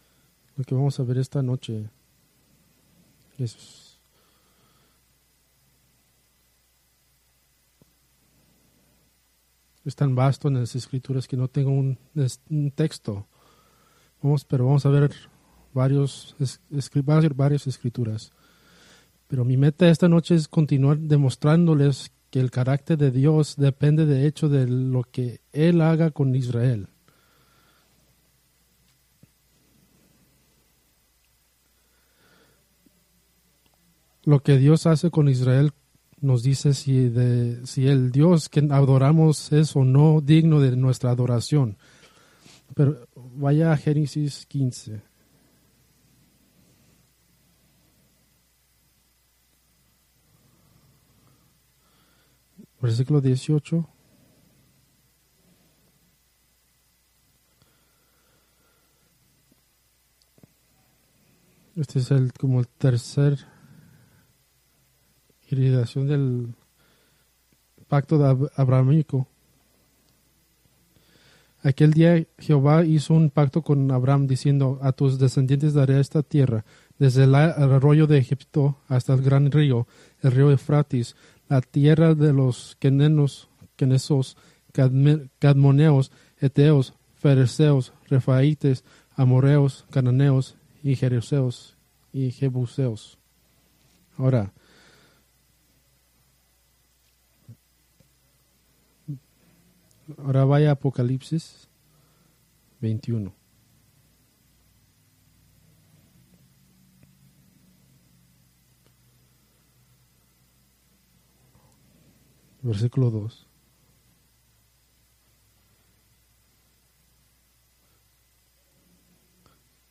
Preached March 30, 2025 from Escrituras seleccionadas